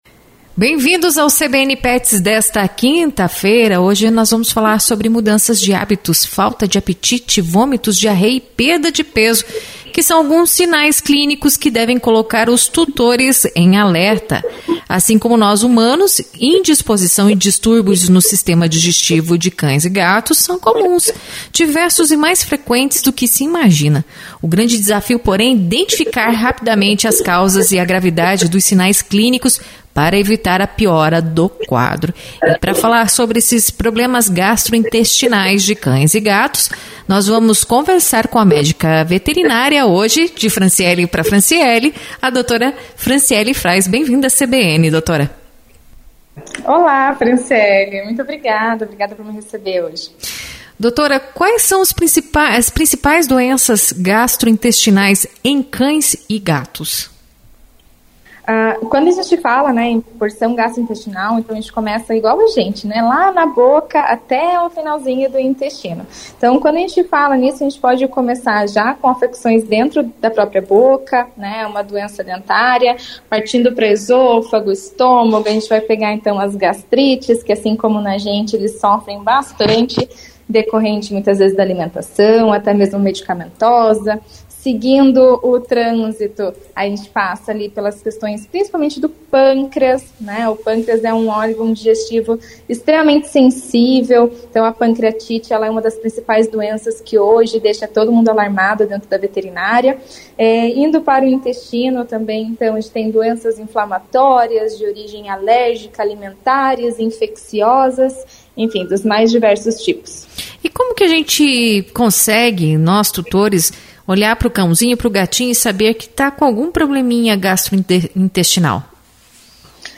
Sobre o assunto conversamos com a Médica Veterinária